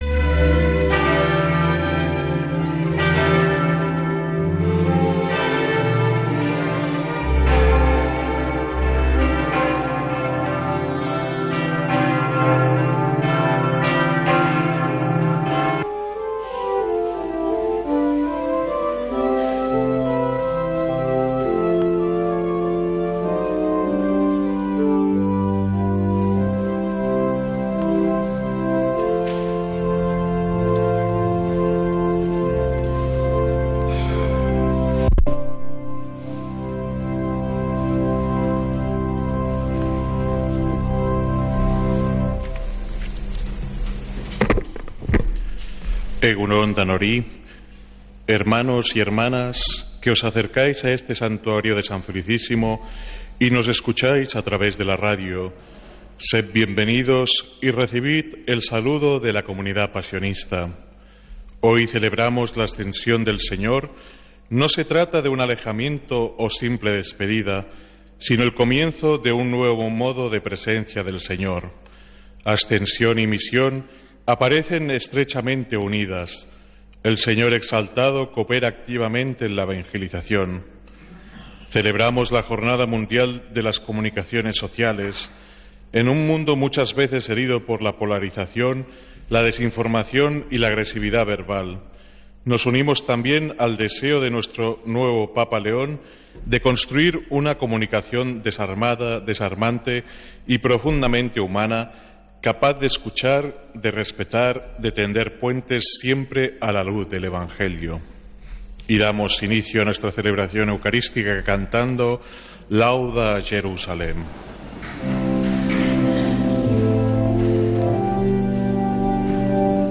Santa Misa desde San Felicísimo en Deusto, domingo 1 de junio